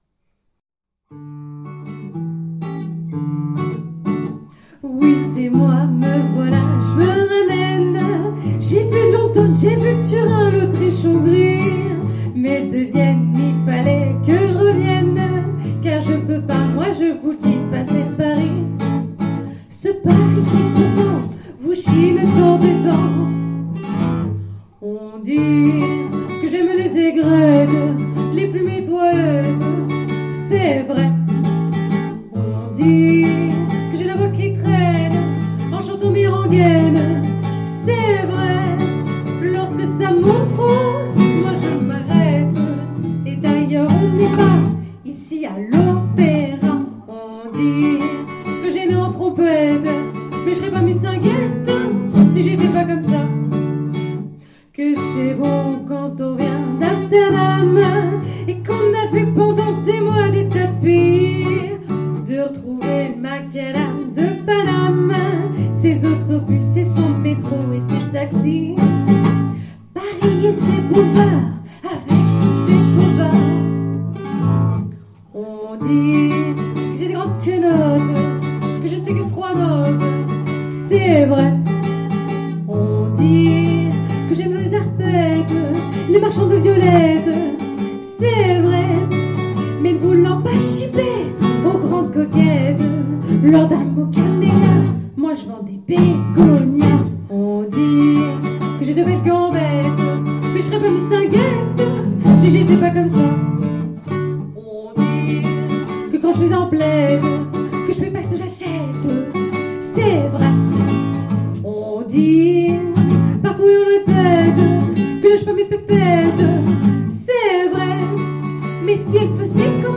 Un concert du genre cabaret qui ne manque pas de pétillant !